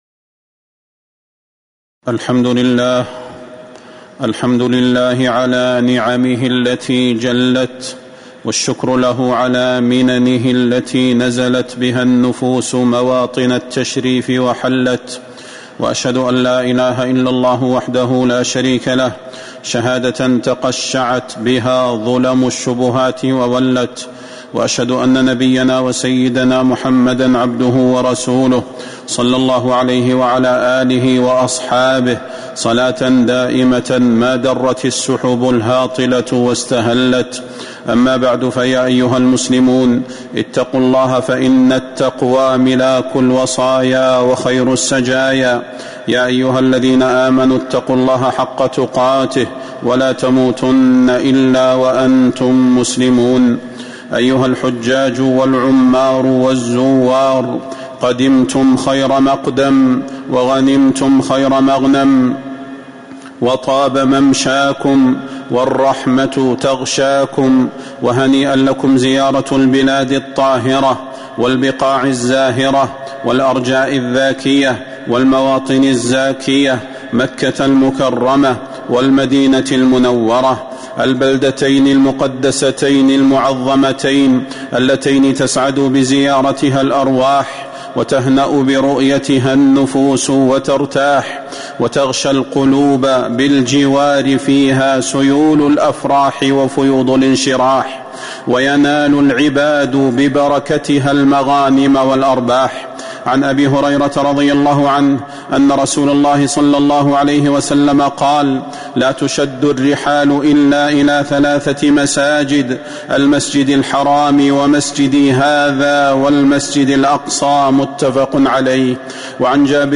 فضيلة الشيخ د. صلاح بن محمد البدير
تاريخ النشر ٢٣ ذو القعدة ١٤٤٥ هـ المكان: المسجد النبوي الشيخ: فضيلة الشيخ د. صلاح بن محمد البدير فضيلة الشيخ د. صلاح بن محمد البدير وصايا مهمة للحجاج والمعتمرين